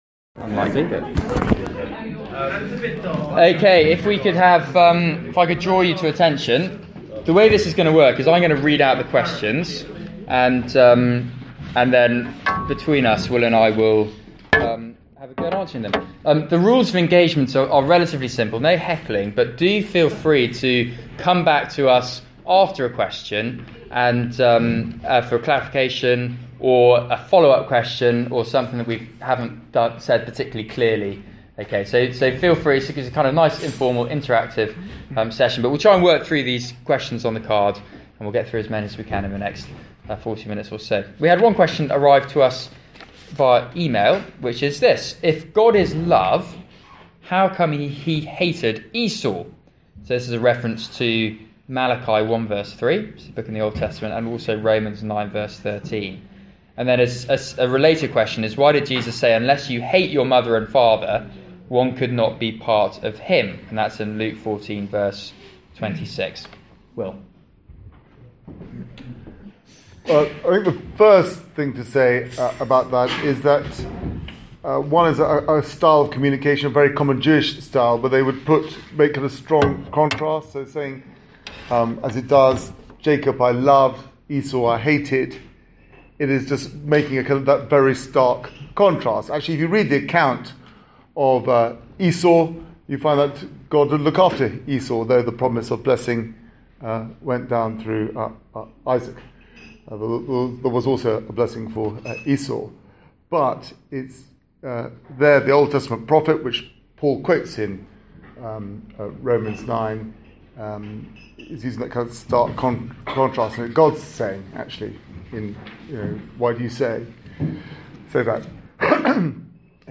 Q&A questions and time on recording